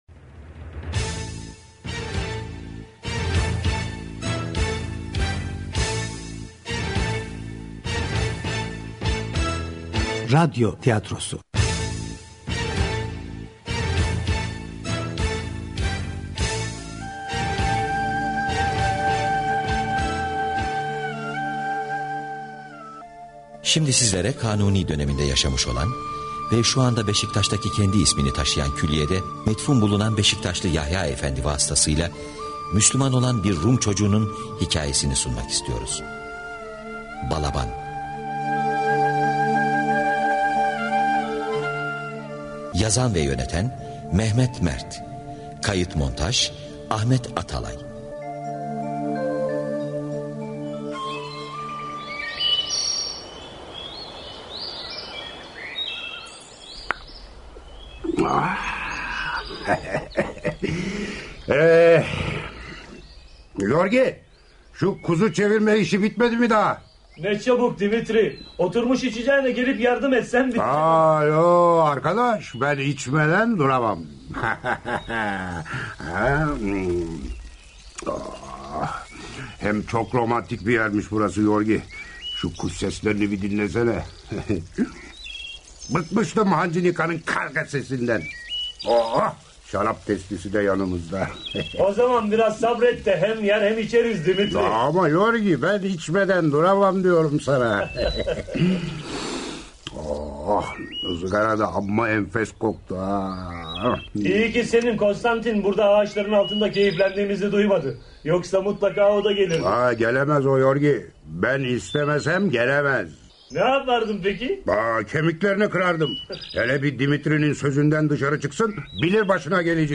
Yahya-Efendi-radoy-tiyatrosu-.mp3